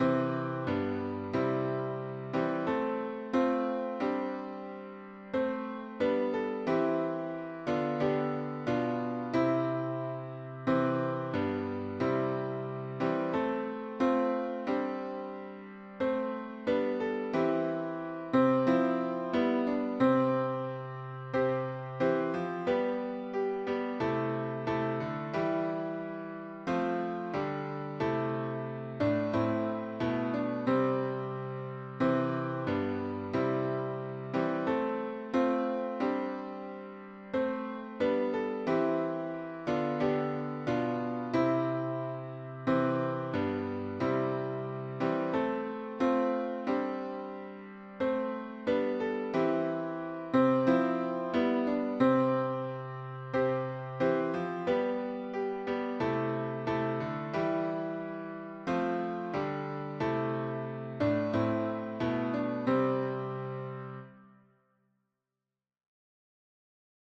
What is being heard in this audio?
SATB Voicing/Instrumentation: SATB